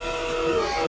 SERVO SE12.wav